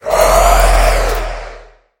sounds / mob / wither / idle2.mp3